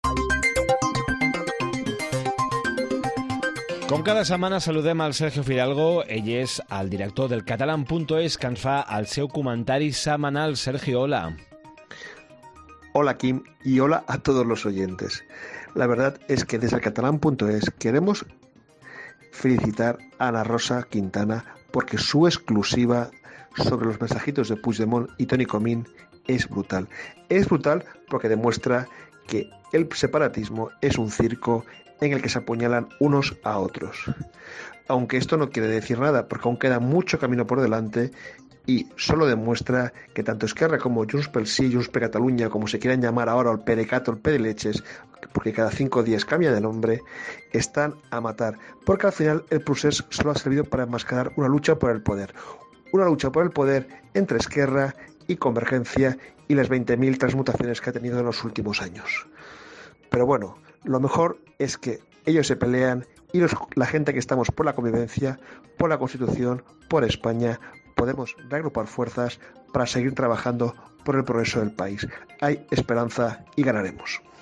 ens fa el seu comentari setmanal